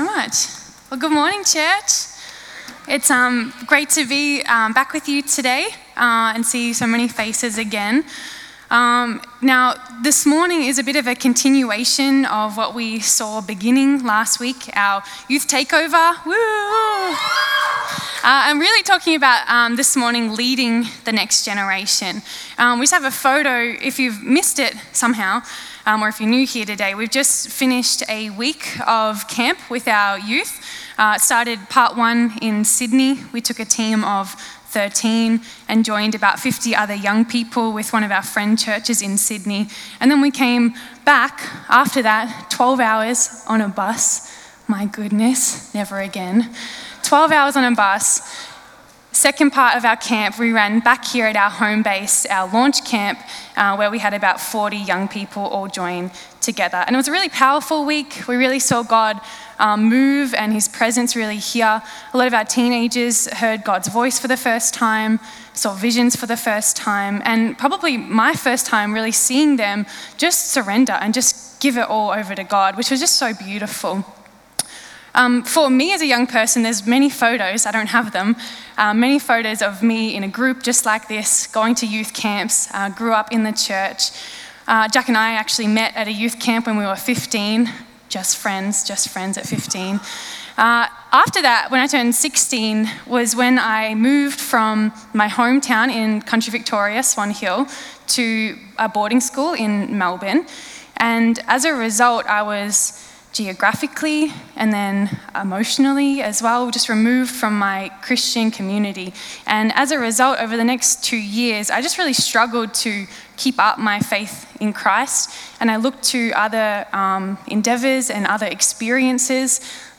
Sermon
Guest Speaker